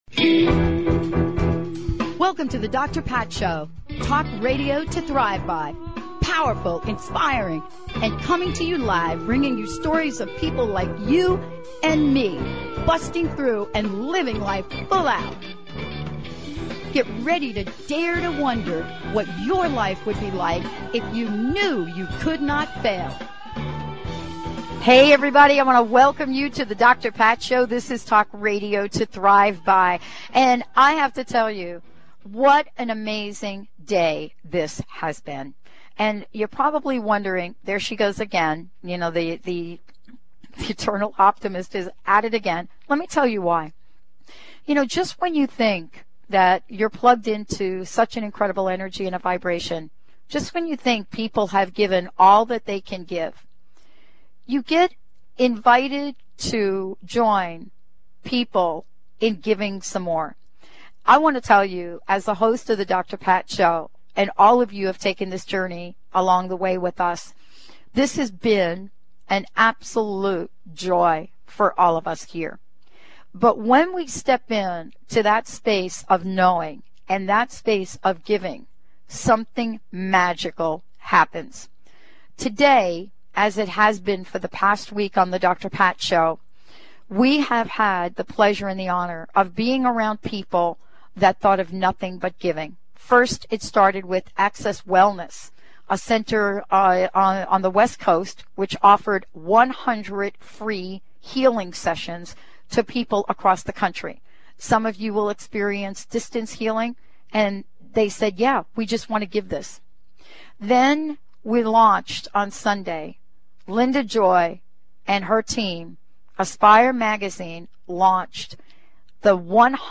Talk Show Episode
Guest: Dee Wallace - Encore Presentation - Choice - How we don't make it and how we make it with Author, Actress, Healer - Dee wallace Date: December 16, 2010 A show on BBS Radio Network